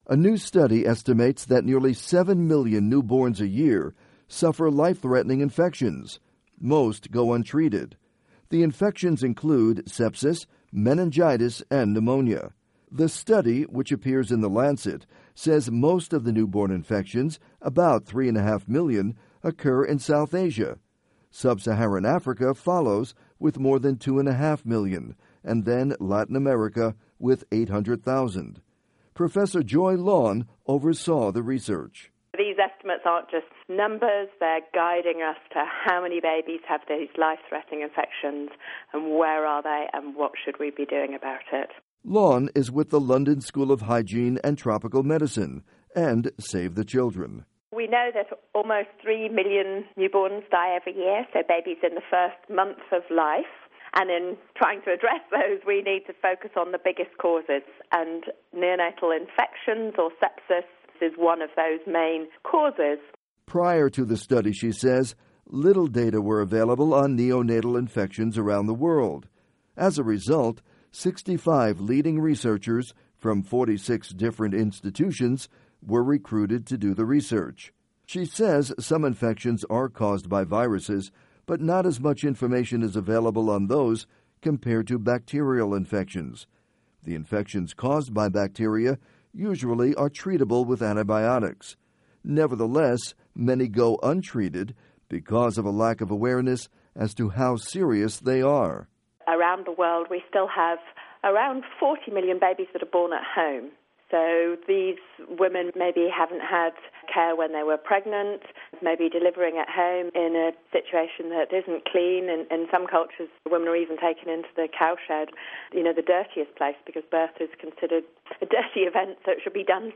report on newborn infections